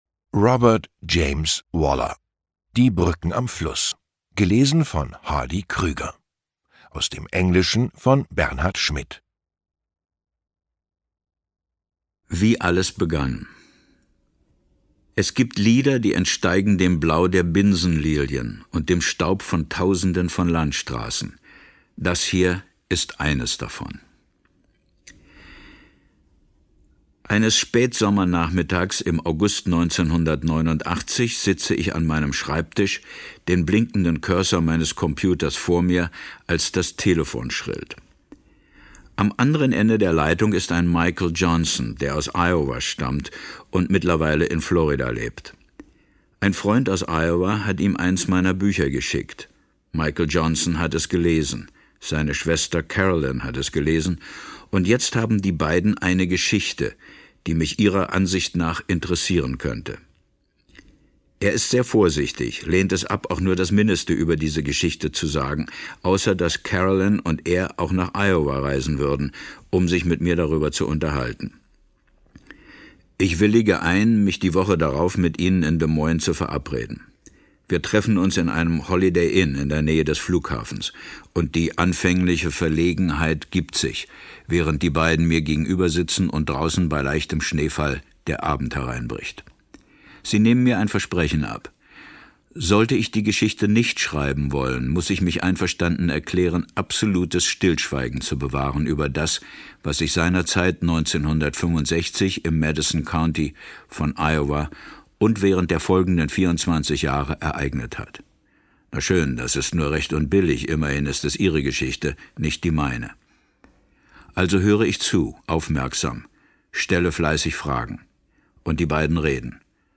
Ungekürzte Lesung mit Hardy Krüger (1 mp3-CD)
Hardy Krüger (Sprecher)